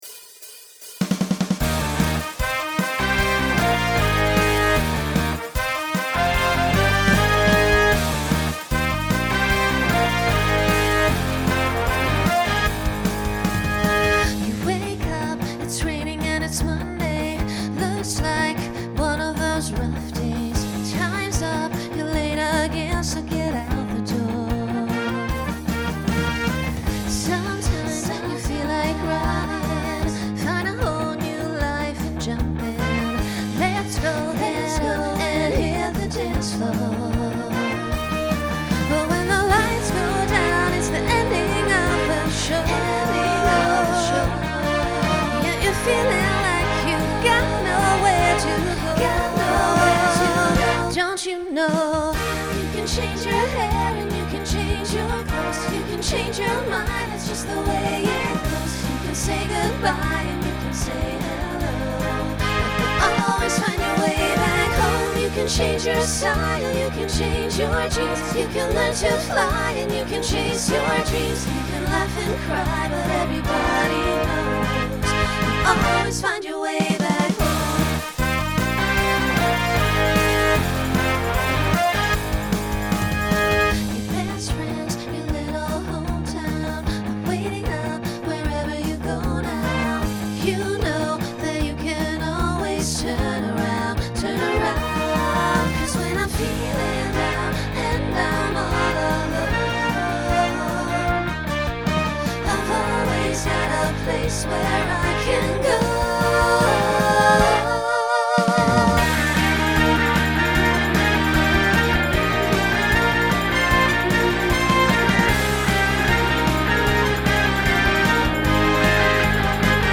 New SAB voicing for 2026.
Genre Broadway/Film , Pop/Dance Instrumental combo